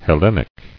[Hel·len·ic]